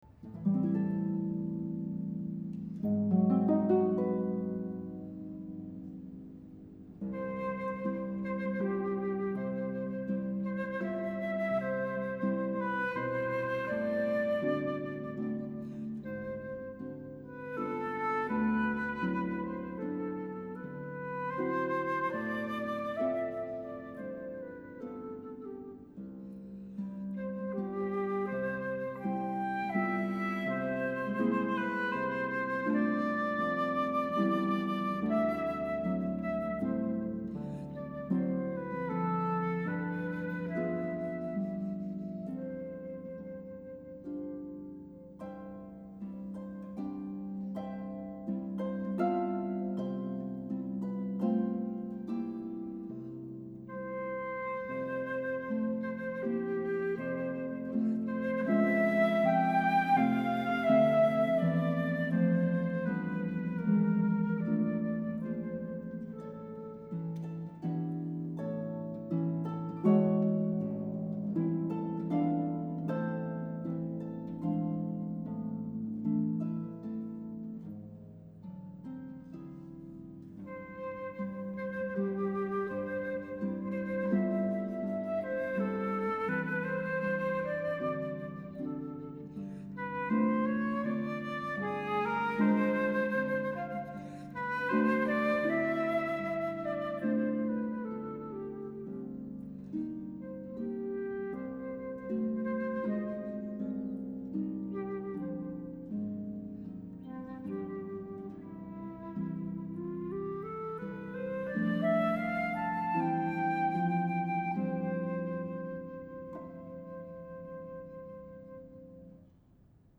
•(01) Quintet No. 2 for flute, string trio and harp
•(14) Quintet for clarinet and string quartet
violin
flute
harp
viola
clarinet
cello